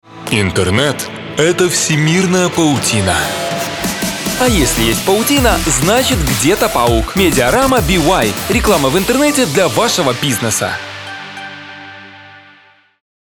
Муж, Рекламный ролик/Средний
RME Babyface pro, LONG, DBX, Digilab, Neumann TLM 103, 023 Bomblet, ARK 87, Oktava MK-105, sE 2200